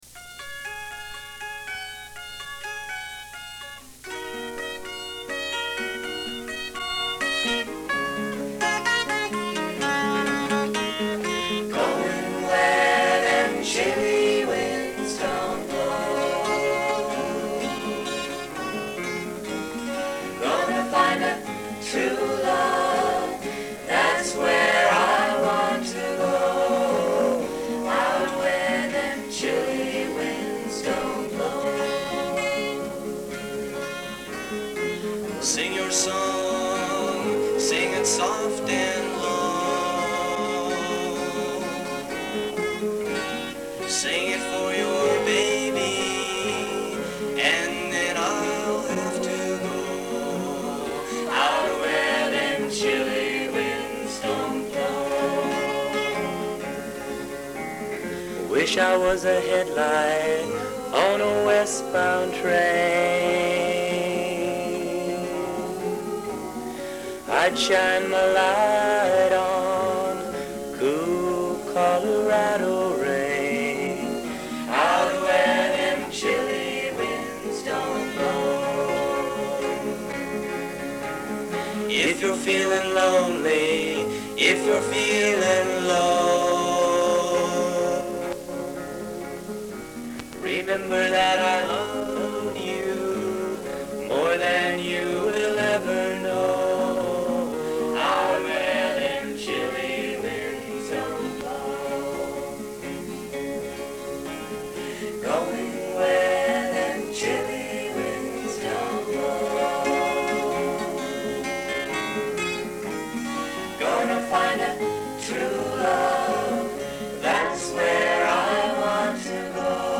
Recorded live when we were 15-17 years old